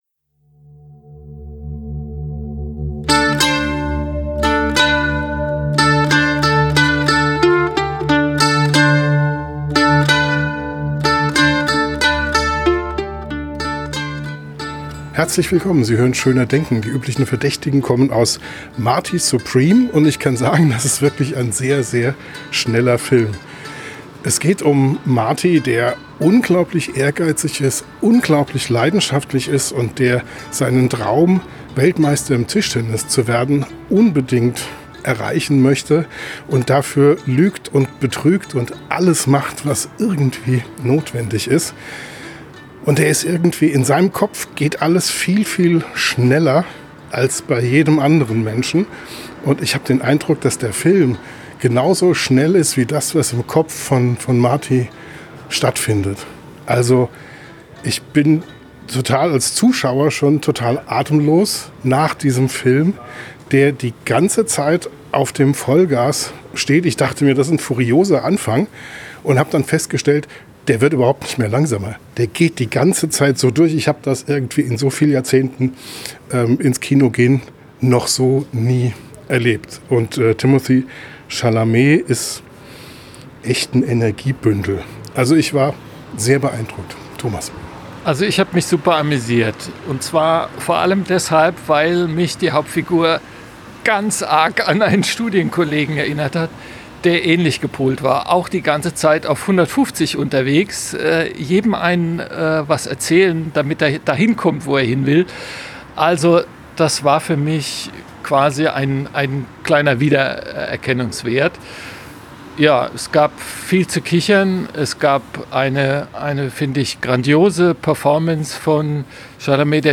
Am Mikrofon direkt nach dem Film